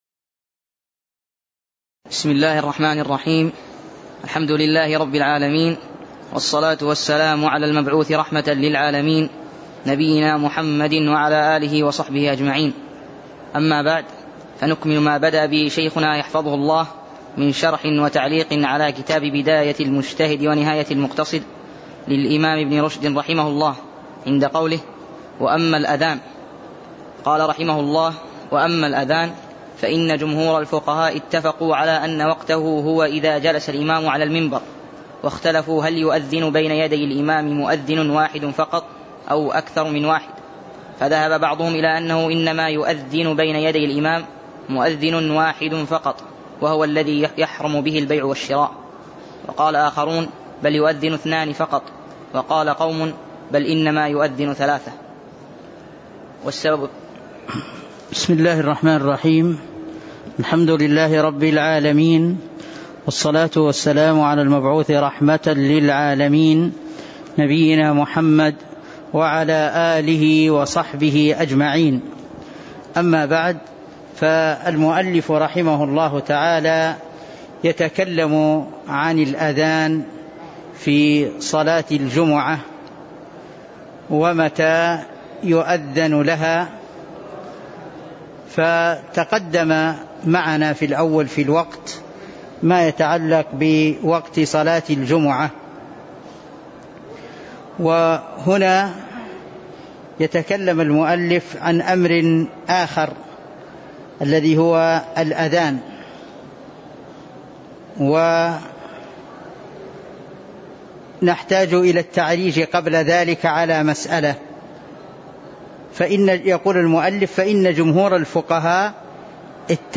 تاريخ النشر ٨ جمادى الأولى ١٤٤٣ هـ المكان: المسجد النبوي الشيخ